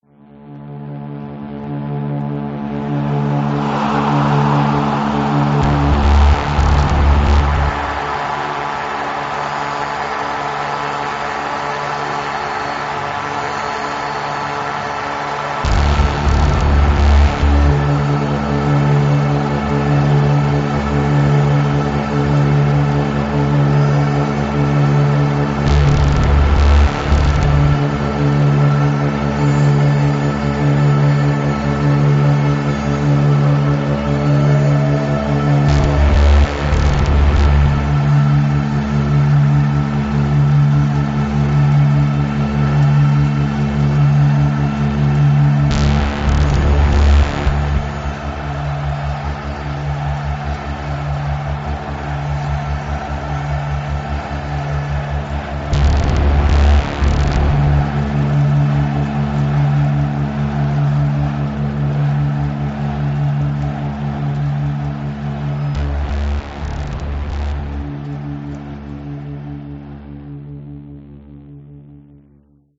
virtex-stadium-noise-3-m.mp3